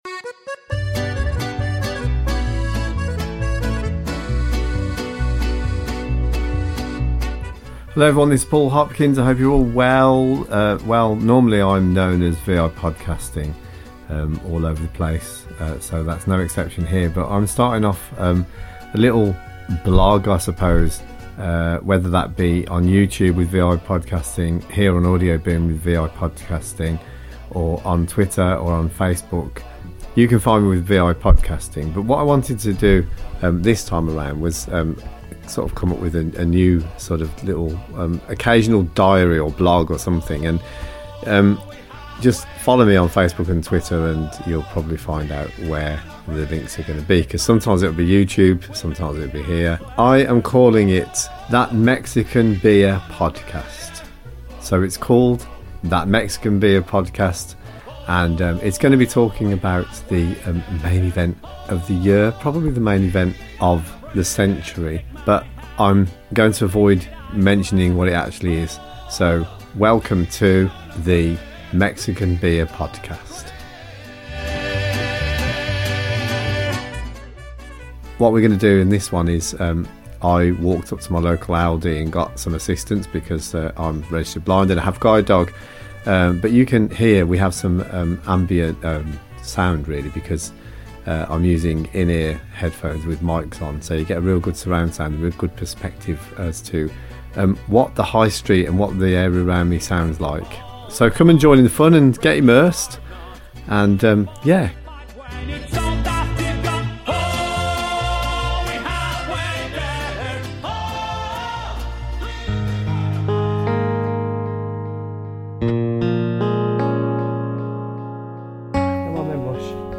All seems quite normal which makes this trip somehow strange as we observe Social Distancing; Quiet streets due to the UK Lockdown as part of the current global pandemic.